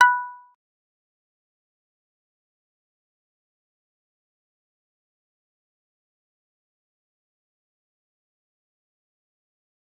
G_Kalimba-B5-pp.wav